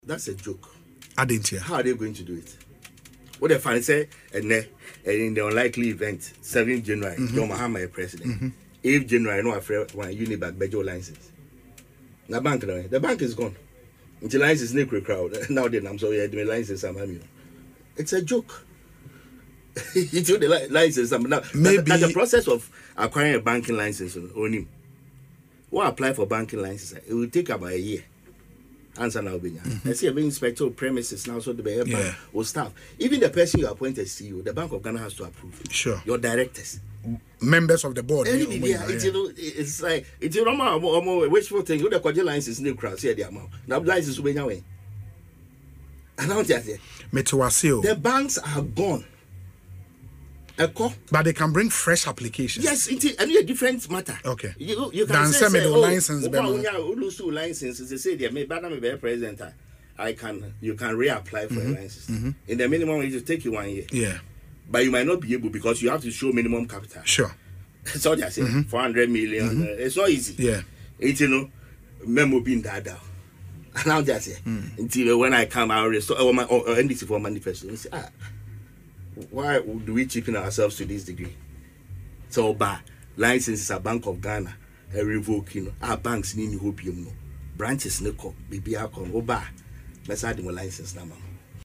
But in an interview on Asempa FM Ekosii Sen programme Wednesday, Mr Assibey-Yeboah stated that, it was just a wishful thinking, adding the banks are non-existent.